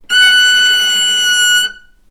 vc-F#6-ff.AIF